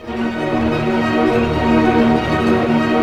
Index of /90_sSampleCDs/Roland L-CD702/VOL-1/STR_Arpeggios/STR_Arpeggios